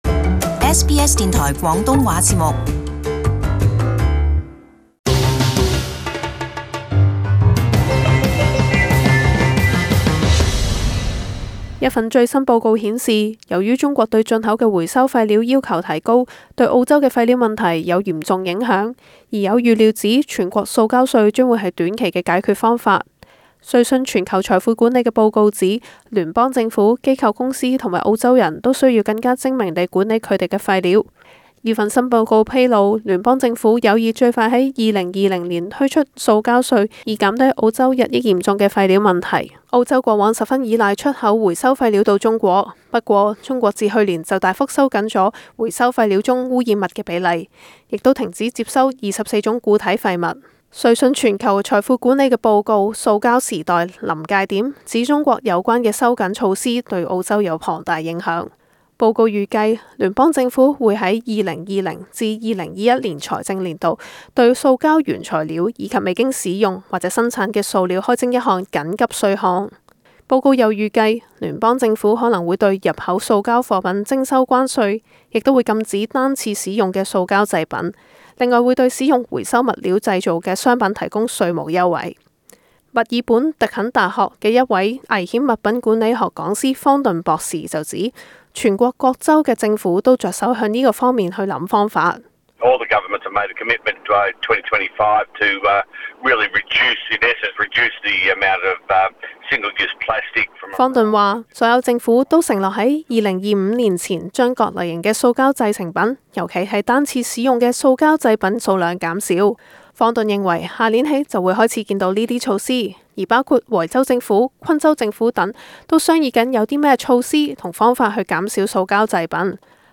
【時事報導】聯邦政府有意推出全國塑膠稅